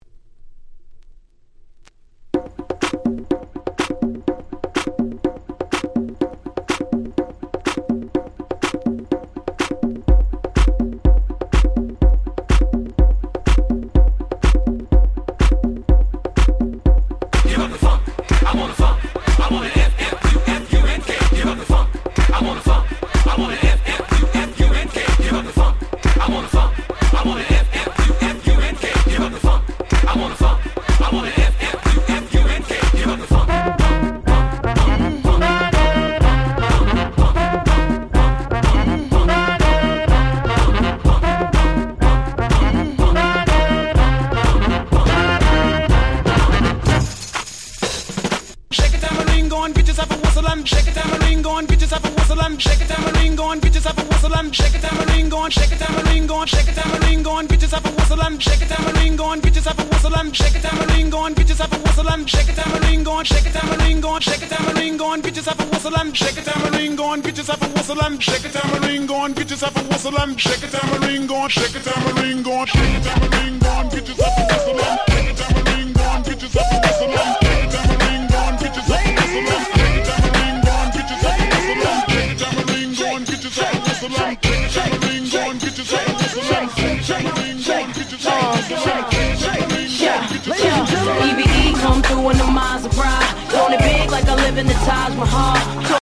DJがPlay中にBPMを変えるのに超便利なトランジション物を全6曲収録しためちゃ使える1枚！！
(124BPM-102BPM)